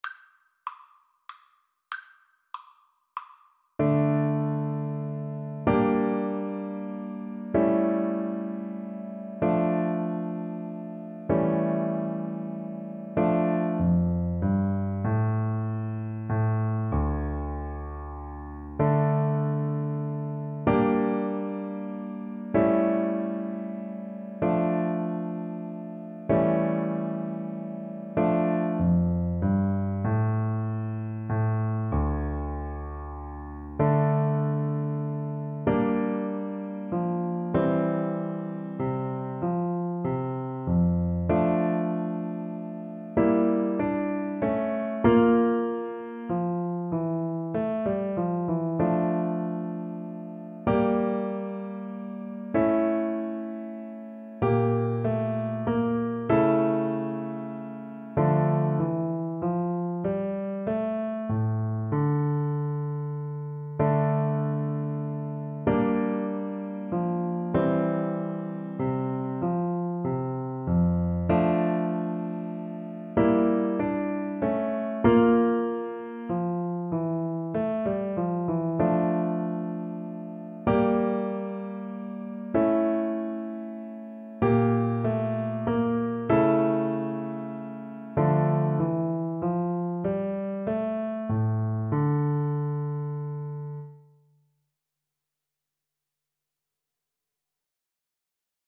3/8 (View more 3/8 Music)
Classical (View more Classical Viola Music)